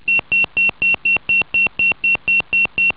Alarmierung
Die Tonrufkombination die benutzt wird um den Funkmeldeempfänger auszulösen, sieht etwa so aus:
Tonruffrequenz
alarm.wav